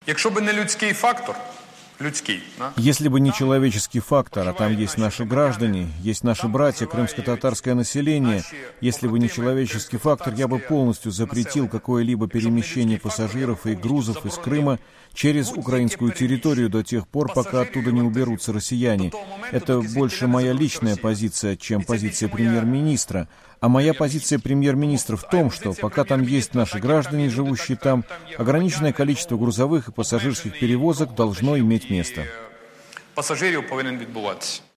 Арсений Яценюк на пресс-конференции в Киеве 30 декабря 2014 года
Яценюк заявил журналистам 30 декабря во время пресс-конференции по итогам года в Киеве, что, если нынешнее поколение украинцев не получит Крым обратно под контроль Киева, то наши дети или внуки получат.